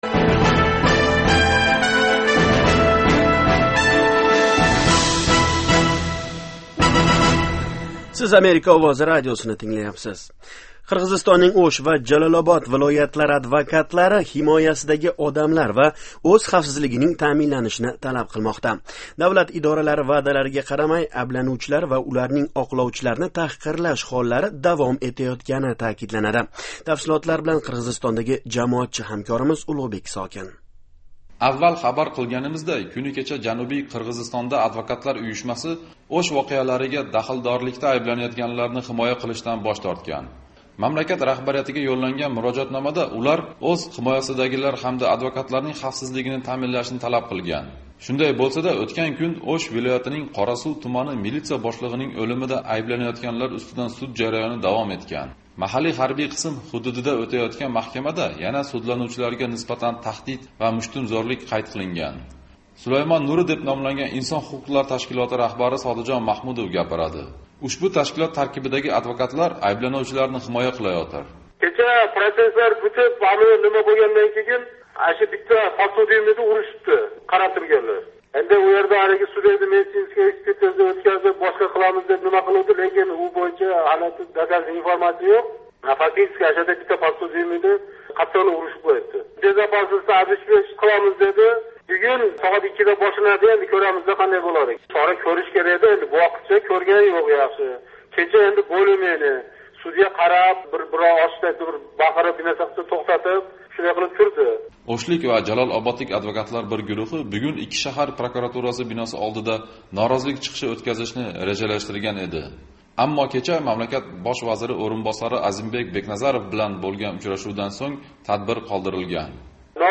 O'shdan reportaj